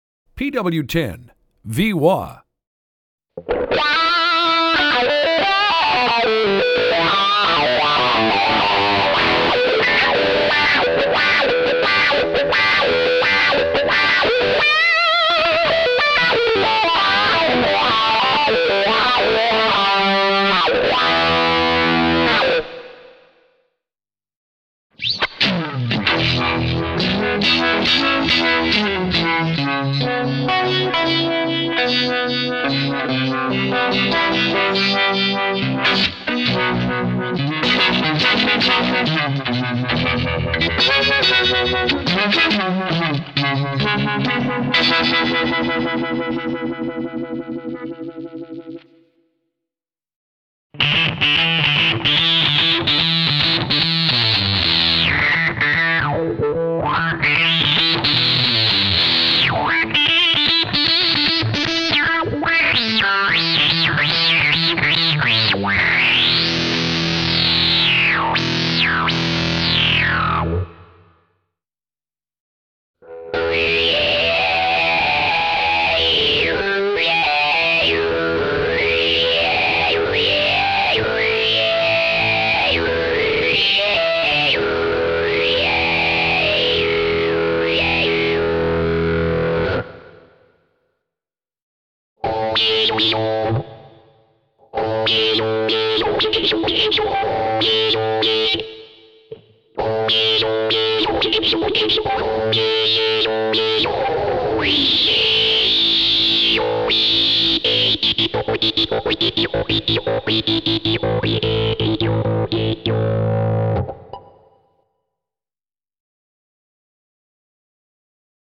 Autres noms du produit:BOSS PW-10 V-Wah guitar effect, BOSS PW-10, BOSS PW 10, BOSS PW10, PW-10, PW 10, PW10
Échantillons sonores BOSS PW-10 V-Wah